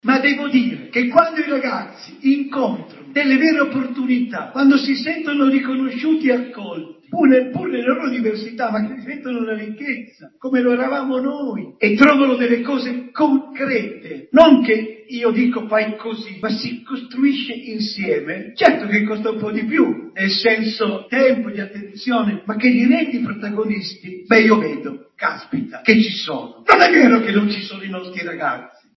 Avete appena ascoltato le parole di don Luigi Ciotti, intervistato nel corso di un evento promosso dalla Caritas Italiana lo scorso 8 marzo.